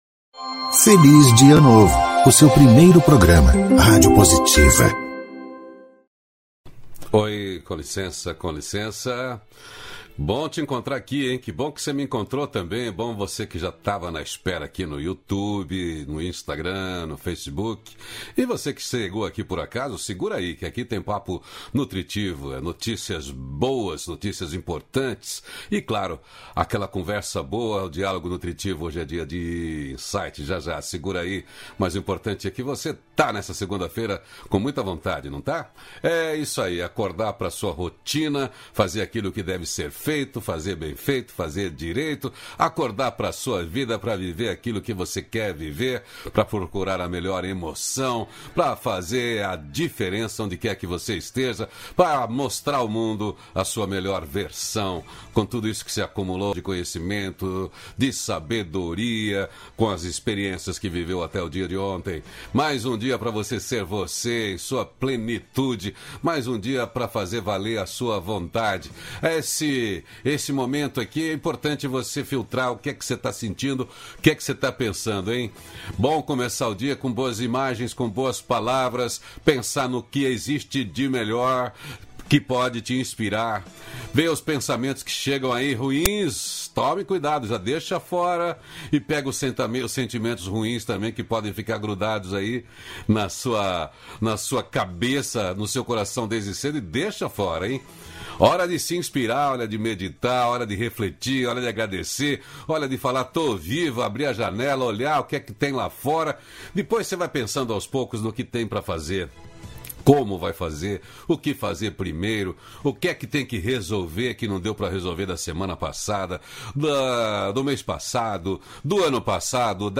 Música: Não há pedras no caminho… Banda Aquática Álbum: Nova Manhã